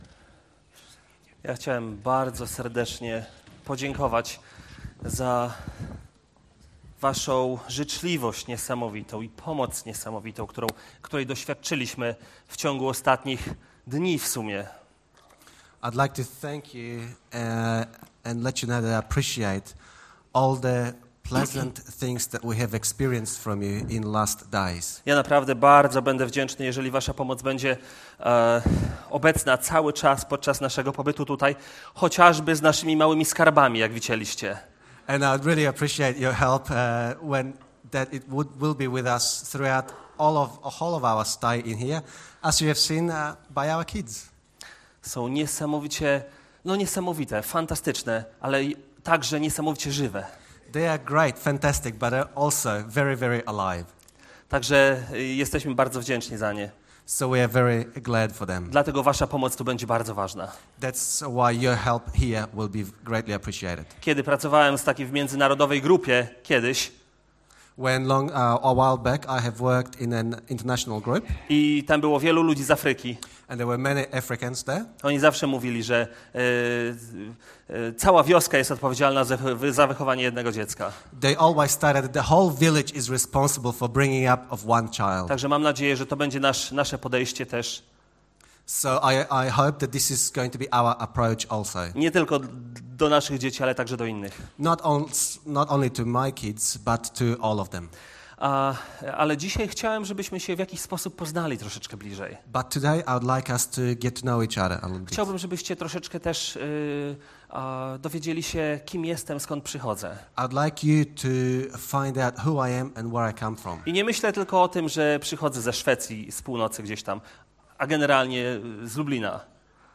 Dandenong Polish-Australian SDA church website